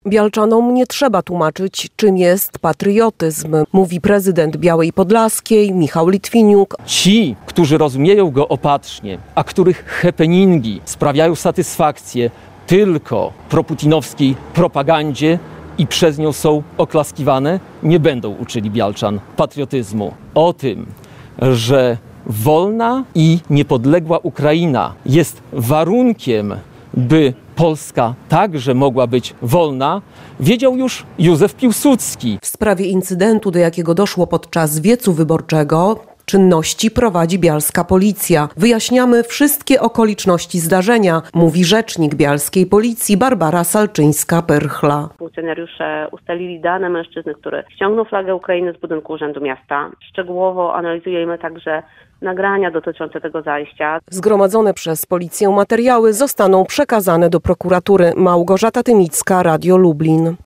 Prezydent Białej Podlaskiej o wiecu Grzegorza Brauna